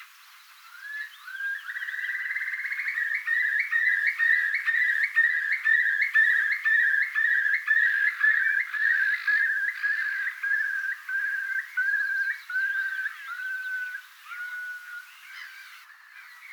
kuovin säe